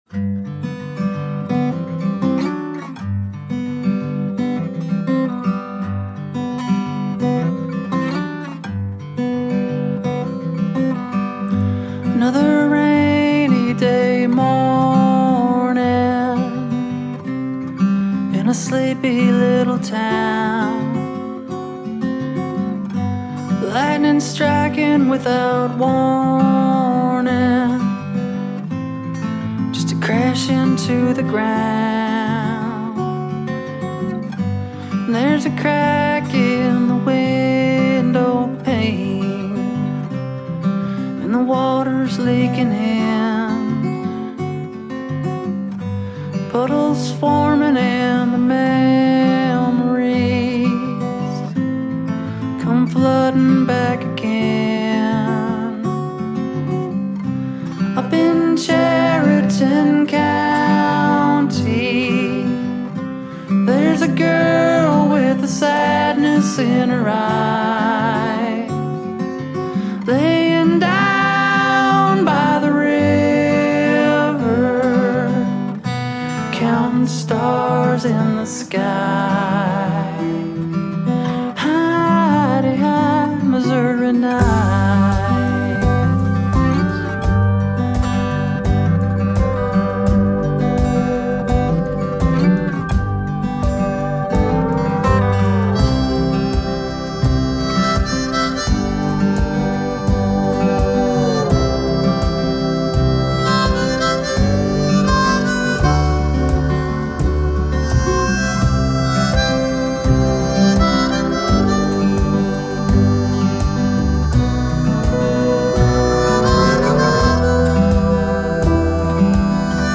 haunting beauty is found in its full and compelling sound